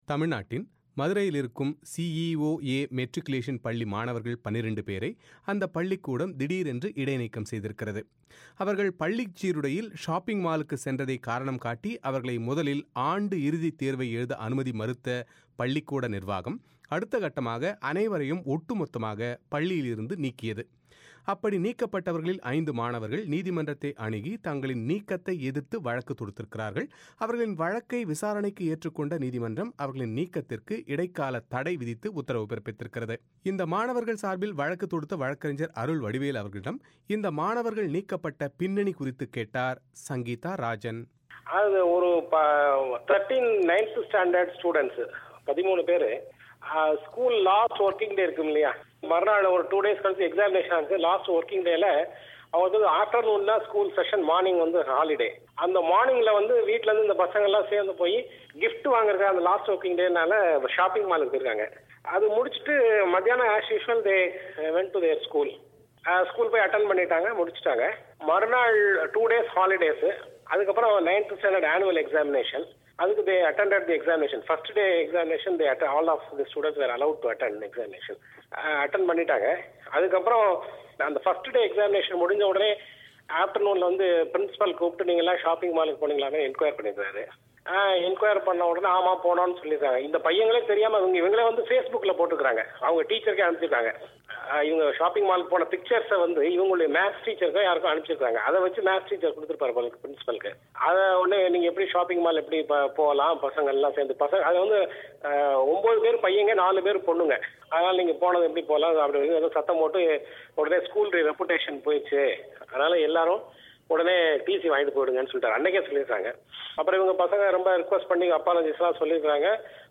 பிரத்யேக செவ்விகள்